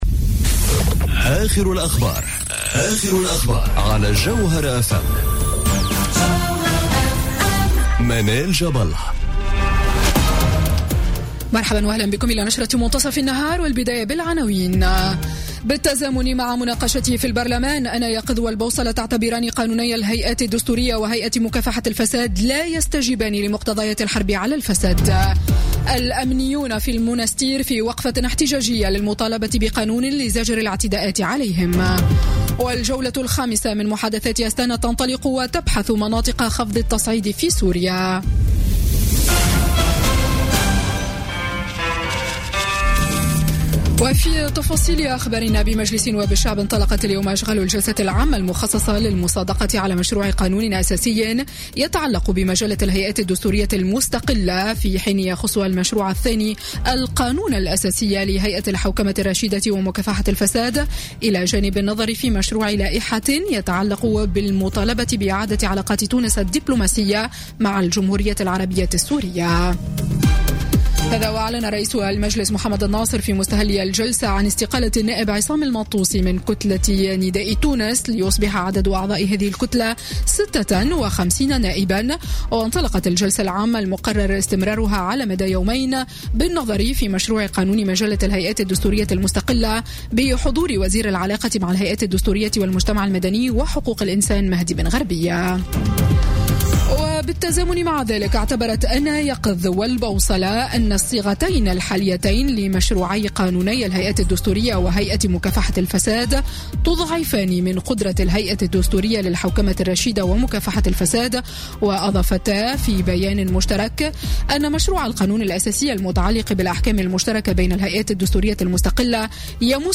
نشرة أخبار متصف النهار ليوم الثلاثاء 4 جويلية 2017